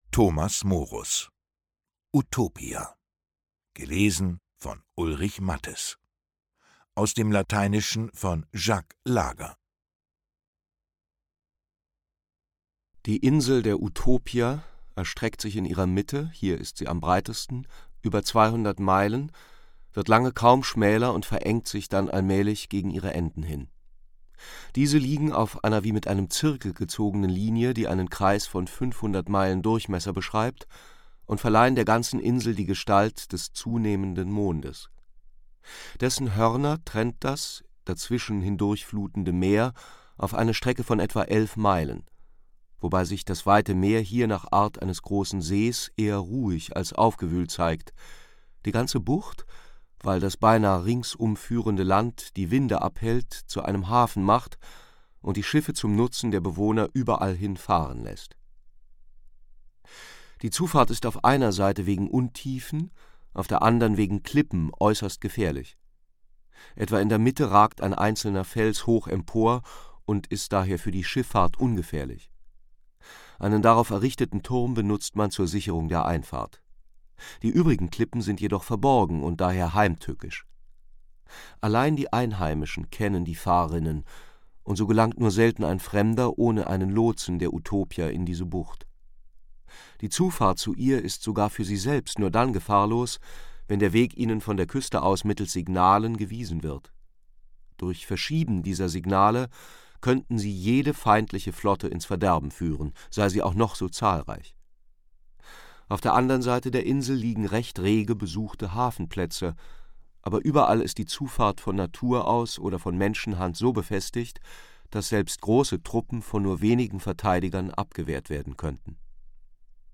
Lesung mit Ulrich Matthes (1 mp3-CD)
Ulrich Matthes (Sprecher)